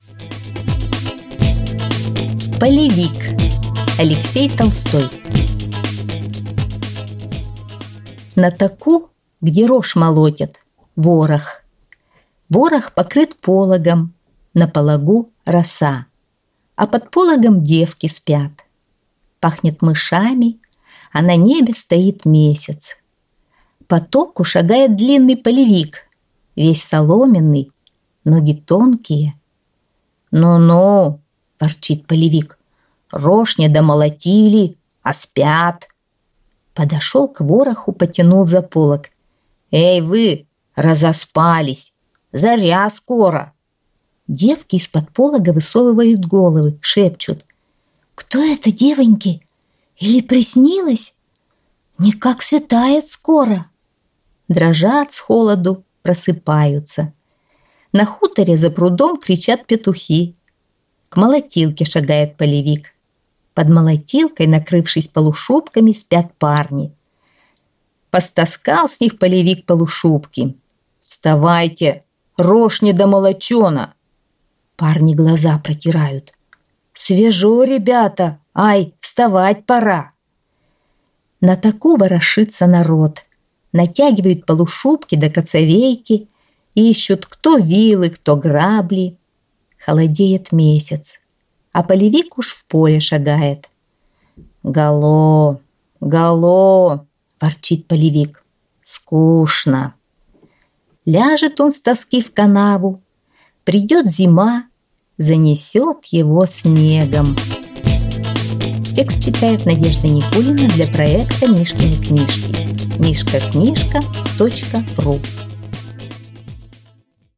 Полевик - аудиосказка Алексея Толстого - слушать онлайн | Мишкины книжки